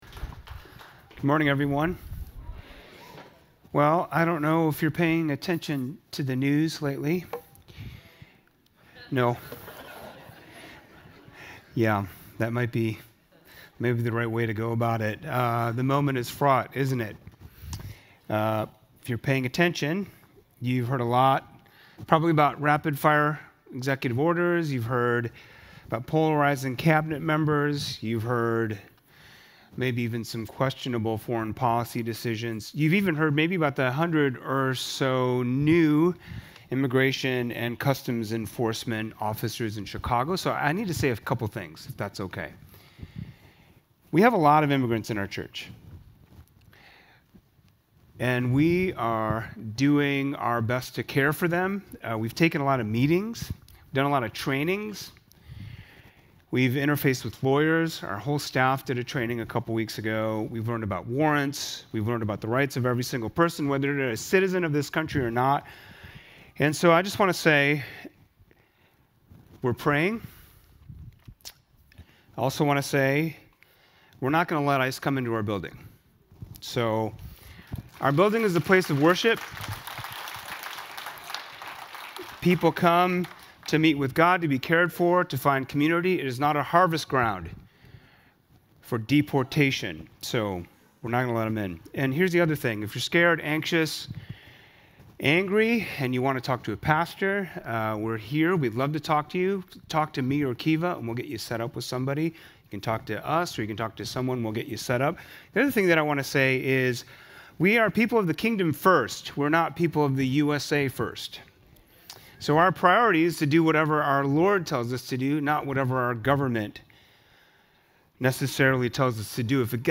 brings this morning's message